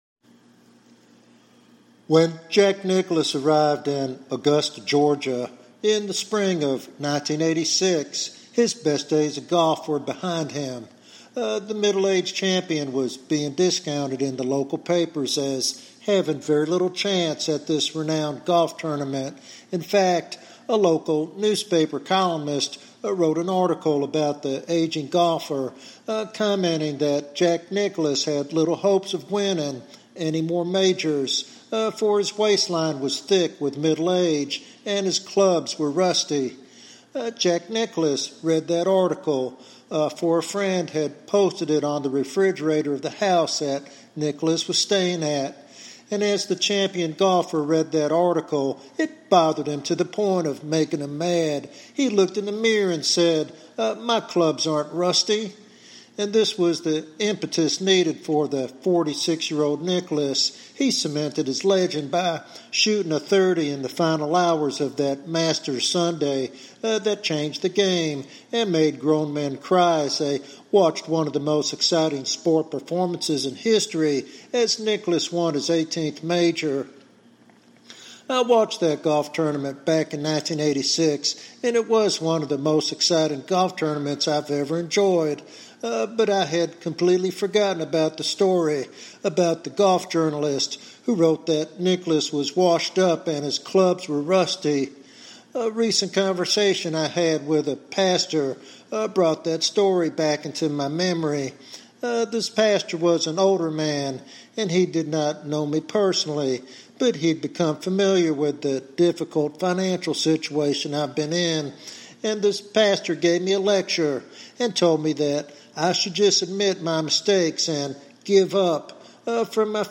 This devotional sermon challenges the church to trust in God's resurrection power and anticipate revival in times of decay and despair.